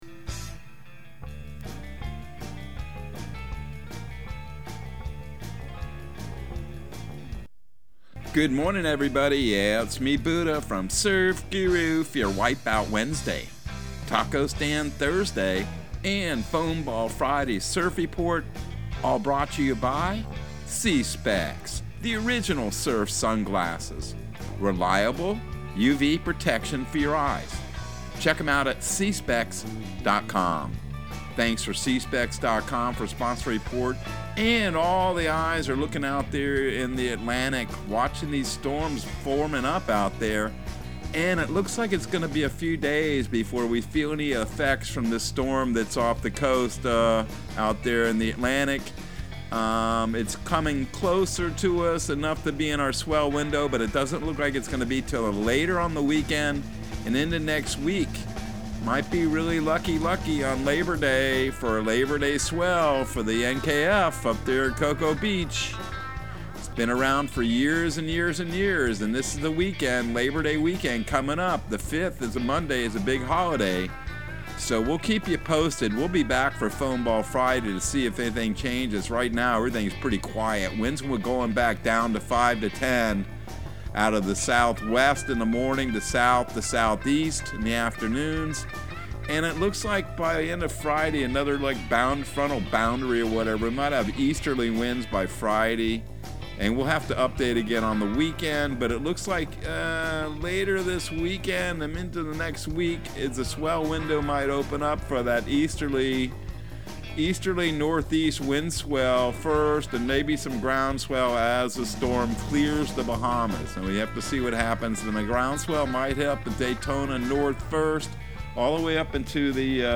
Surf Guru Surf Report and Forecast 08/31/2022 Audio surf report and surf forecast on August 31 for Central Florida and the Southeast.